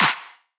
normal-hitclap.wav